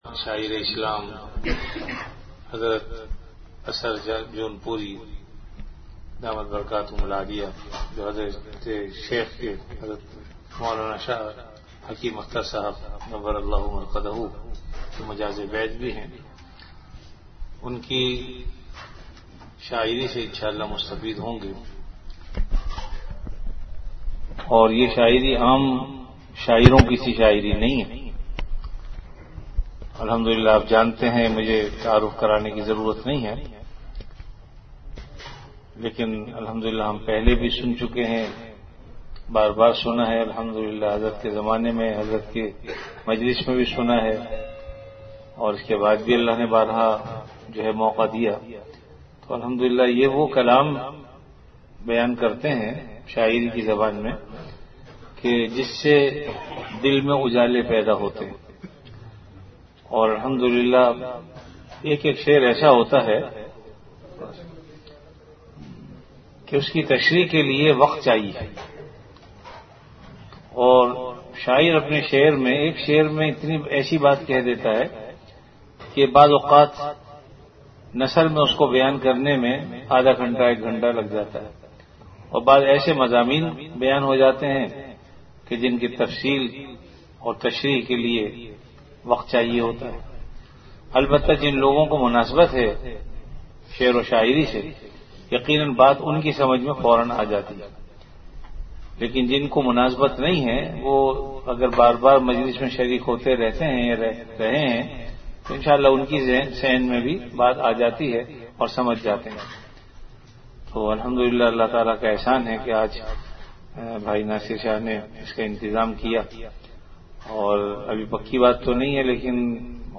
Delivered at Home.
After Isha Prayer